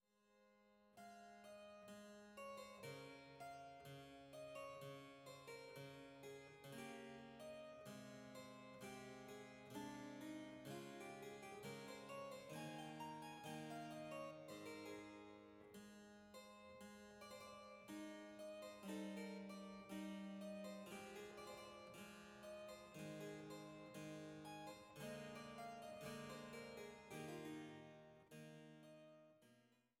Sopran
Cello
Cembalo